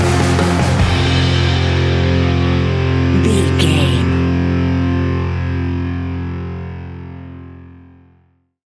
Ionian/Major
F♯
hard rock
heavy rock
distortion